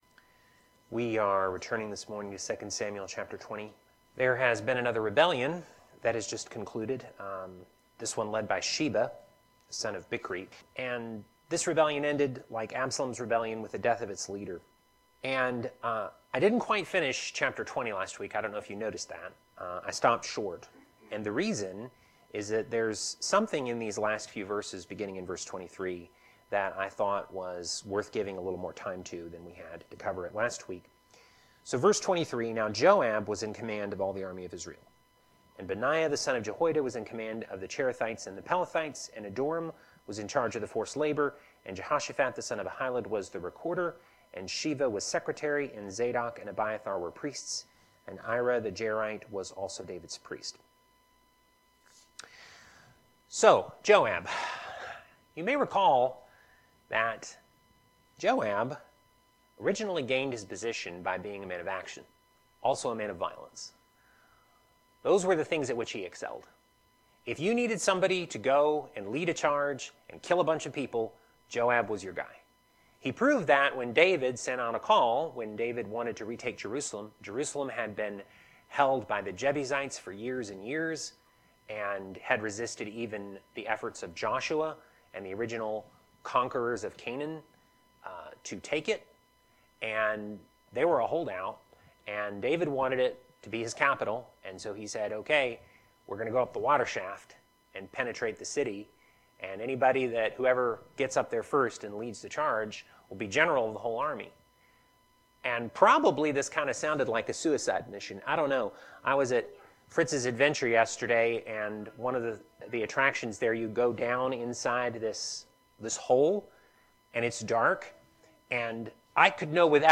Teaching For February 16, 2025